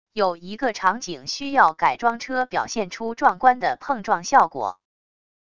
有一个场景需要改装车表现出壮观的碰撞效果wav音频